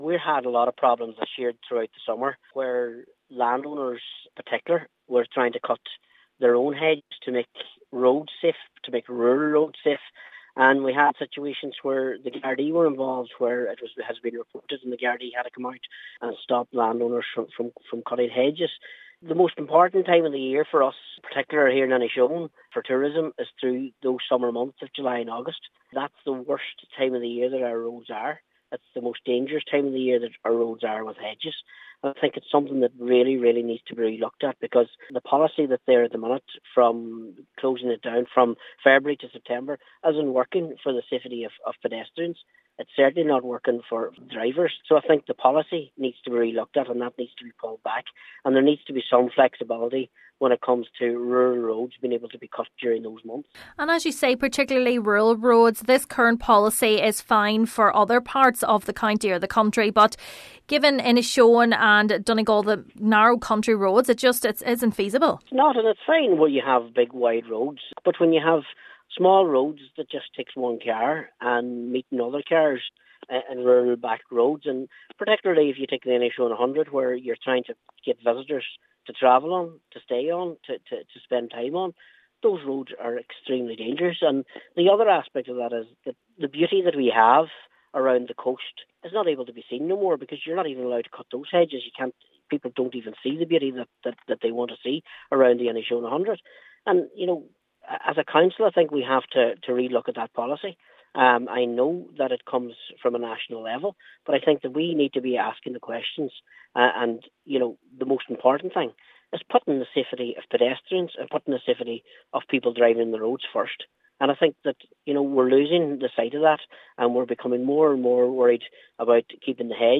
Councillor McDermott says efforts to promote the area to tourists are also being hampered: